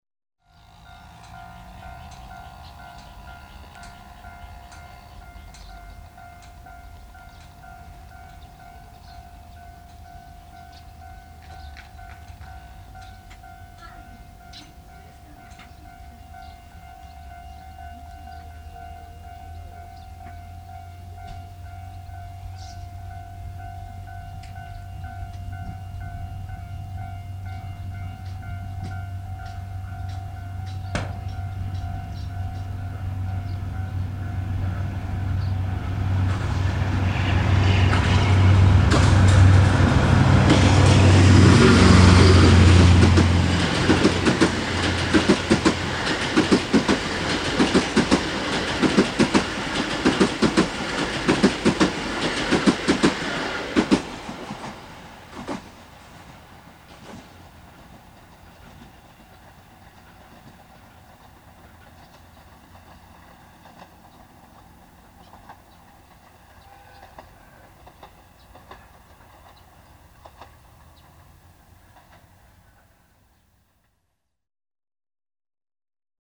ＤＤ５４牽引の客車列車の通過音
国鉄福知山線宝塚駅から６００ｍほど大阪寄りの遠方信号機のあたり
左手の踏み切り警報機が鳴り始めたところ。 周りの人々の生活の音も聞こえる。 暫くして右手の踏み切りも鳴り始めた頃、宝塚駅を出発して加速中のＤＤ５４のエンジンの唸りが左手から聞こえ始める。 レールから響くＢ−１−Ｂの車輪音と、緩い上り勾配を加速する全開のエンジン音がどんどん近付き、轟音と共にＤＤ５４は目前を通過する。 後に続く客車達もＤＤ５４に引かれている事が嬉しいかのように軽快に車輪を響かせる。 目の前を通り過ぎた列車は、車輪の音と微かなディーゼルの排気臭を残して遠ざかって行く･･･。
ラジカセによる録音の為、録音レベルはオートマチック調整されていますが、比較的良好な音質で、ステレオならではの臨場感もあります。
ＳＯＮＹステレオラジオカセットＣＦ−６５００「ジルバップ」（マイク内蔵）でナショナル・テクニクスのノーマルテープに録音したものを、ＤＡＴを介してＭＰ３に変換。